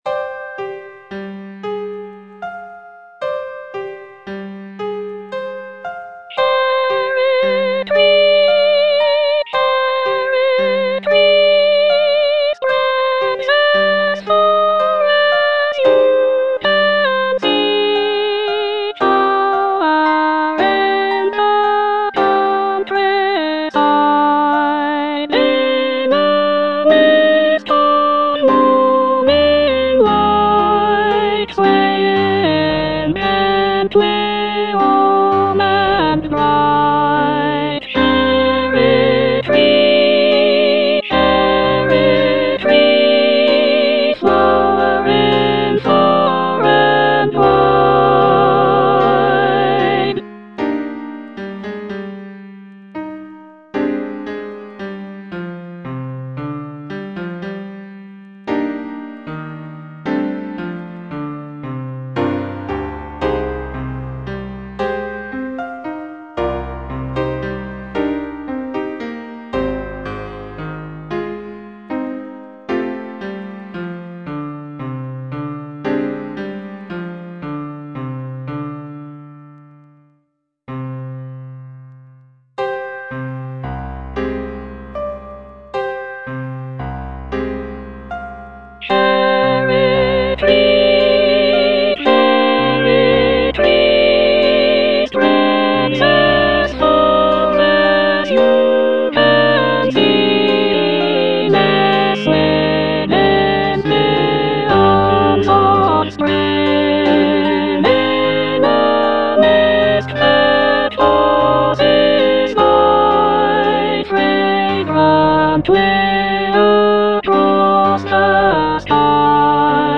Soprano (Emphasised voice and other voices) Ads stop